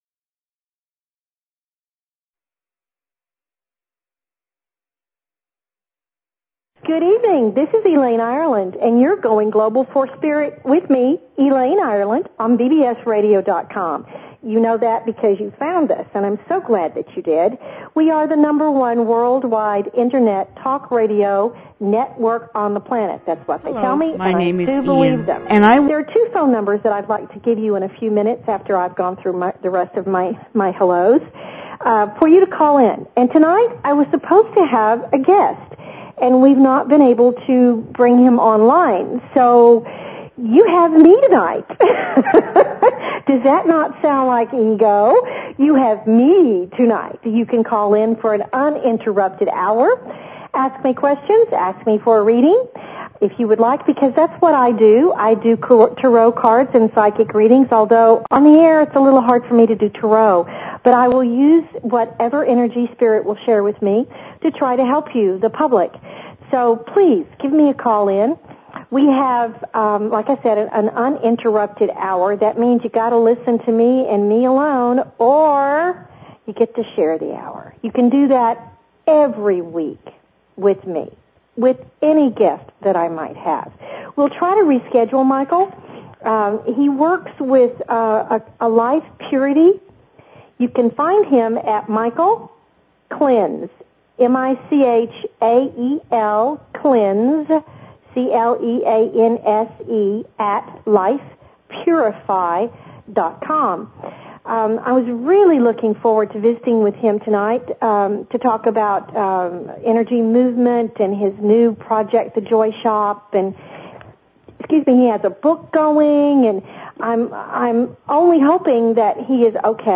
A variety of guests will be here to teach and share their wonders with you. They invite you to call in with your questions and comments about everything metaphysical and spiritual!"